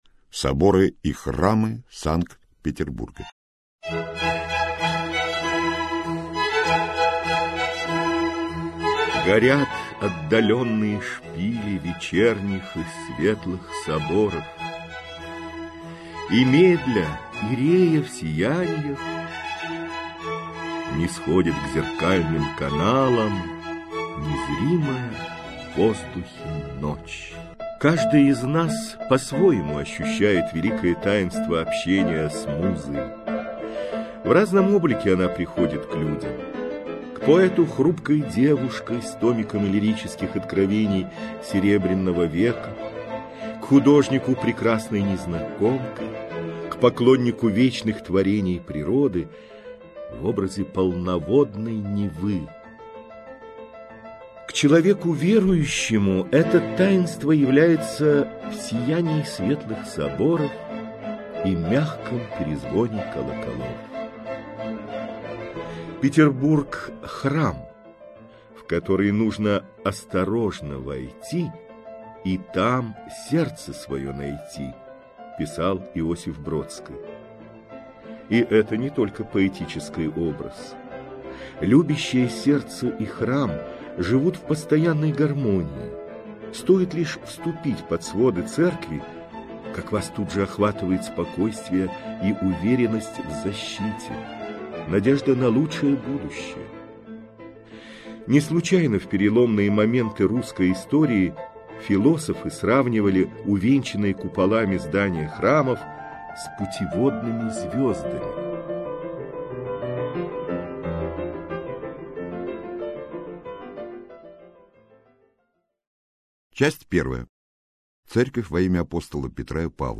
Аудиокнига Соборы и Храмы Санкт-Петербурга. Путеводитель | Библиотека аудиокниг